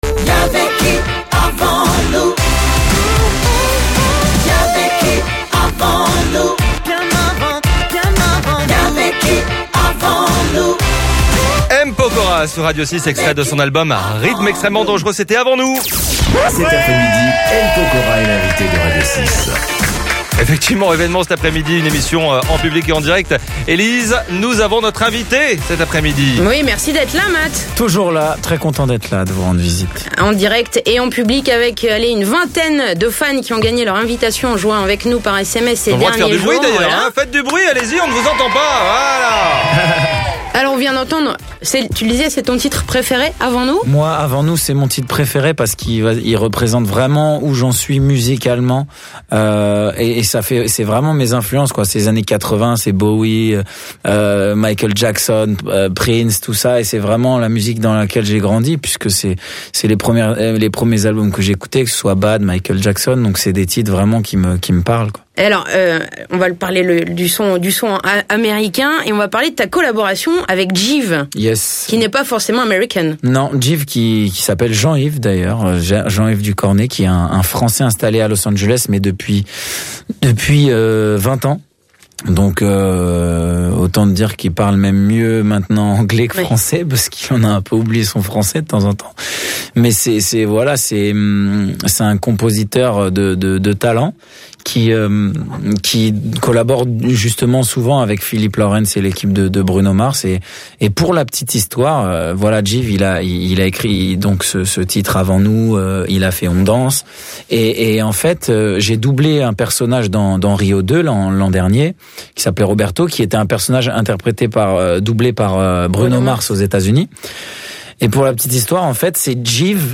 2eme partie de l'émission en direct et en public du mercredi 25 février 2015 ! promo RED !